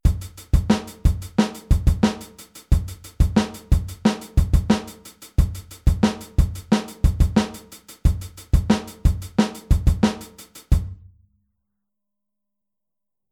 Aufteilung linke und rechte Hand auf HiHat und Snare